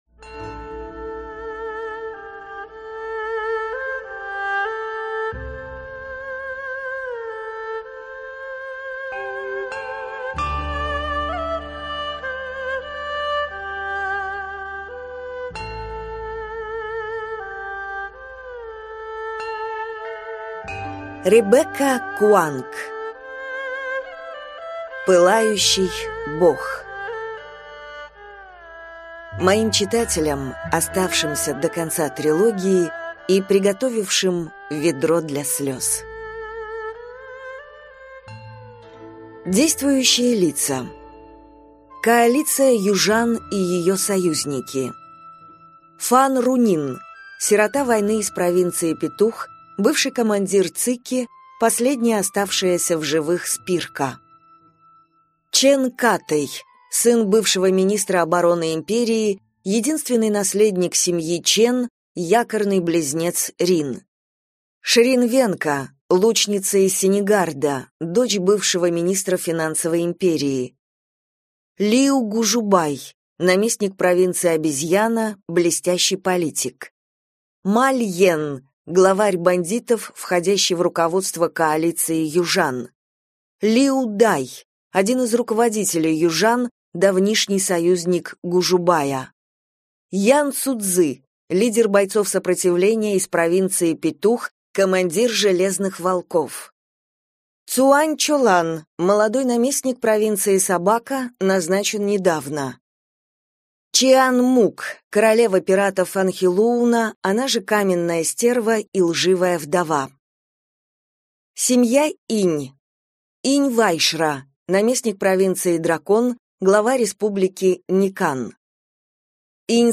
Аудиокнига Пылающий бог | Библиотека аудиокниг